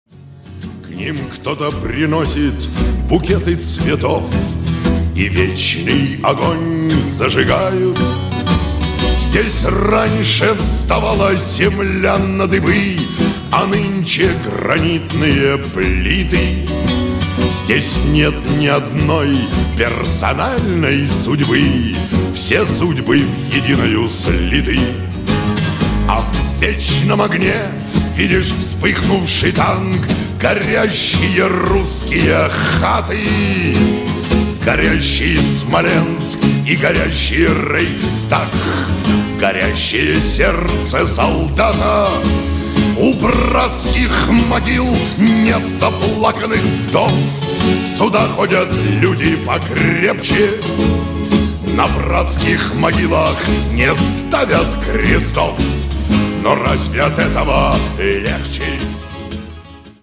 гитара
печальные
солдатские
авторская песня